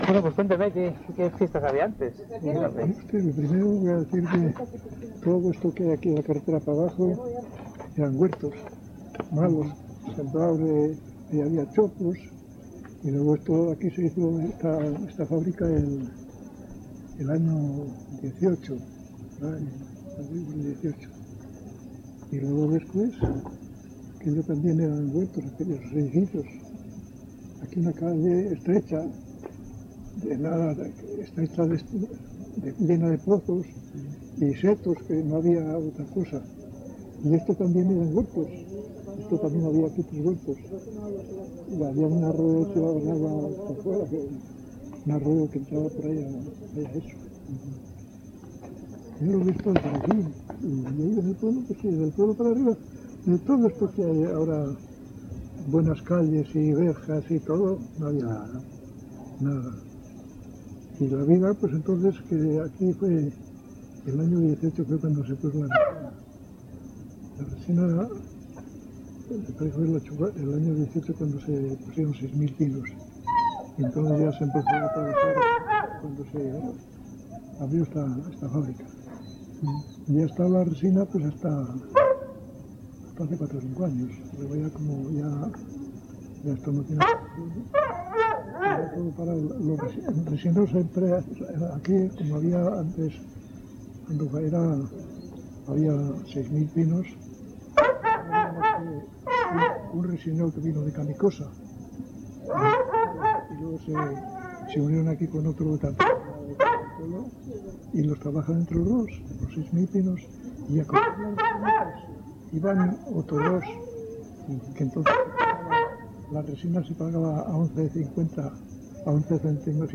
Locality Cubilla